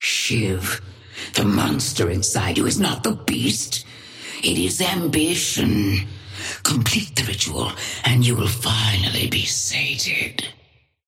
Patron_female_ally_shiv_start_04_alt_01.mp3